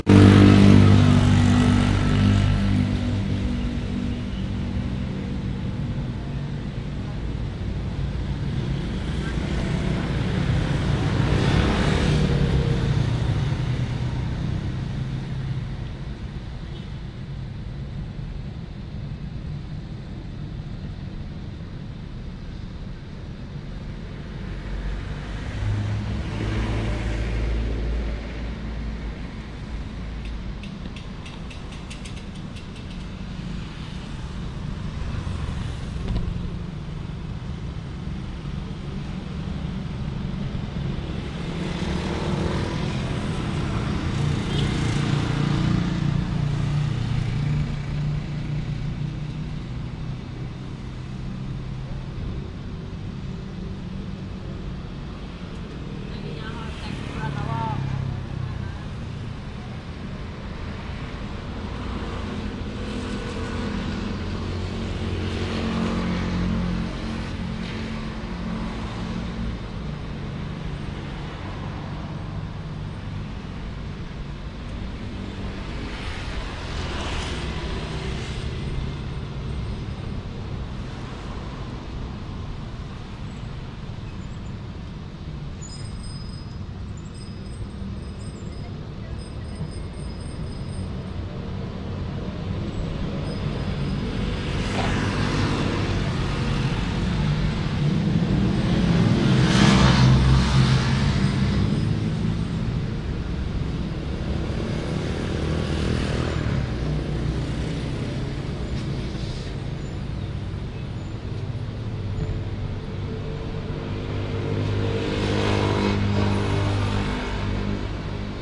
街头声音日1
描述：汽车和自行车经过的街道一般噪音，在正常的中午用Rode Videomic Pro录制。
Tag: 午盘 音景 环境 汽车 交通 街道 早晨 环境 汽车 现场录音 噪音 大气 城市 声音